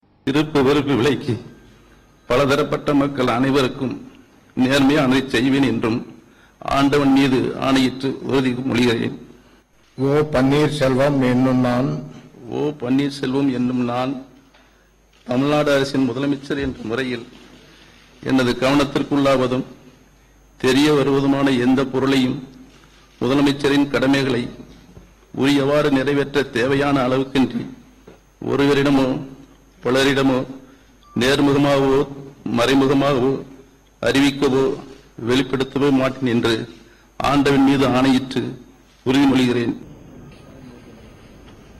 தழுதழுத்த குரலில் பதவியேற்று கொண்ட ஓ.பன்னீர்செல்வம்
தமிழகத்தின் புதிய முதல்வராக இன்று அதிகாலை பொறுப்பேற்று கொண்டார் ஓ.பன்னீர்செல்வம். ஆளுநர் வித்யாசாகர் பதவி பிரமாணம் செய்து வைக்க தழுதழுத்த குரலில் பதவியேற்று கொண்டார் ஓ.பி.எஸ்